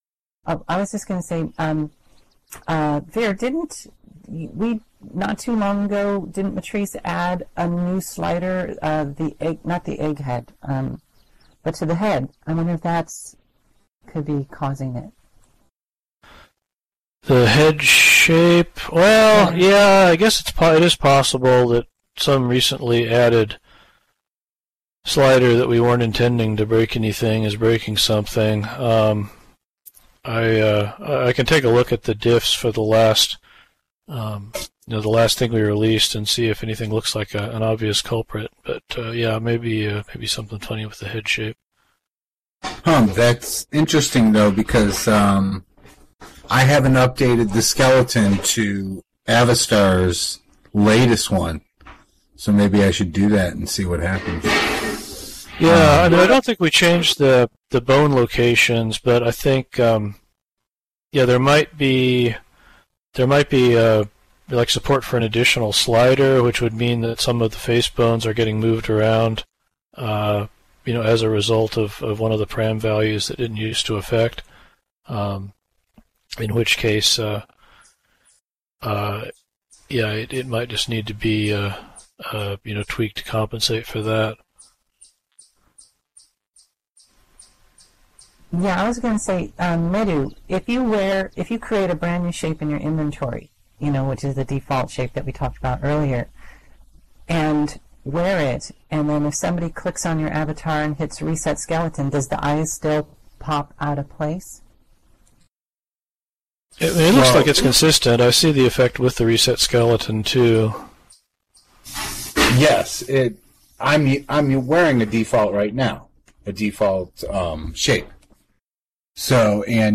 The following notes and audio were taken from the weekly Bento User Group meeting, held on Thursday, June 16th at 13:00 SLT at the the Hippotropolis Campfire Circle .